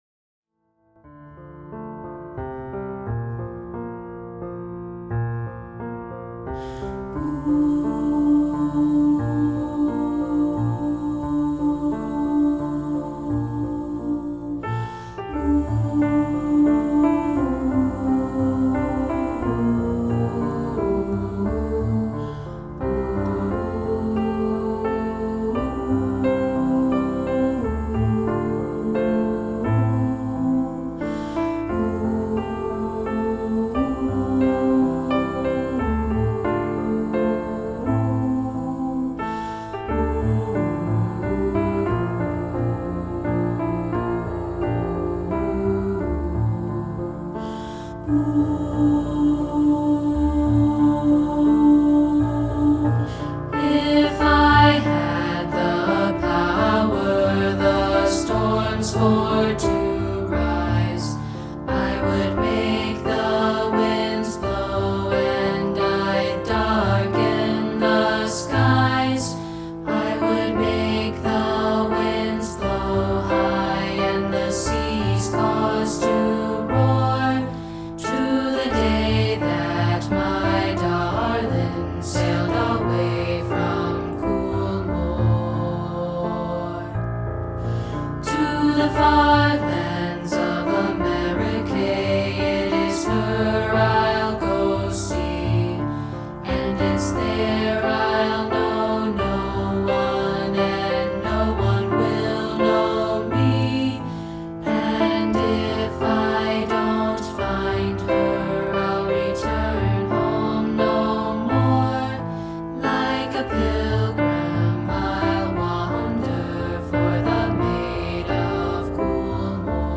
traditional Irish song
This is a rehearsal track of part 3, isolated.